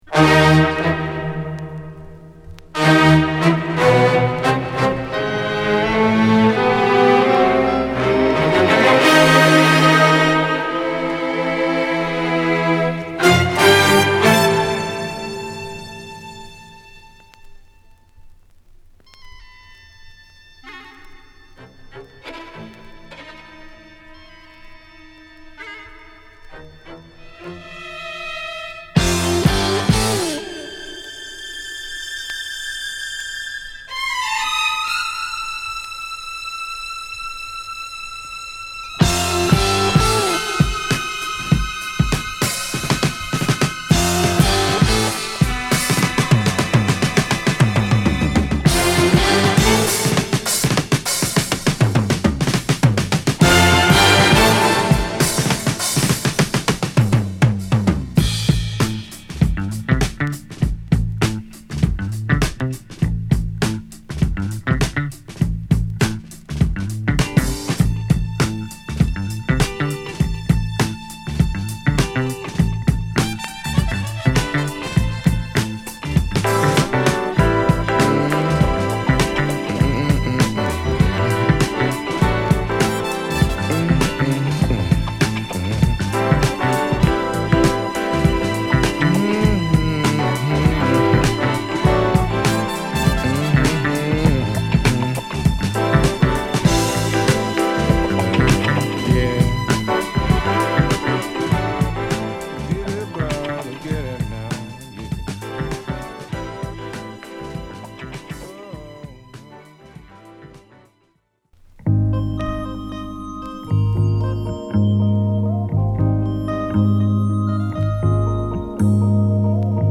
西海岸発の人種混合ファンク〜ロック・グループ